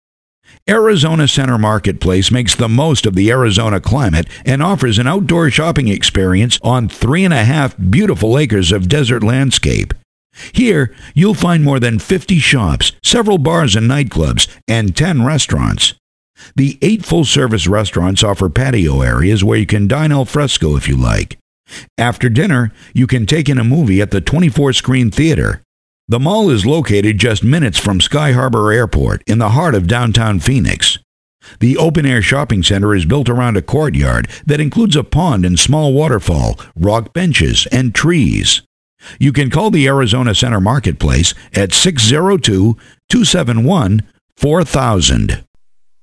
Phoenix Audio Travel Guide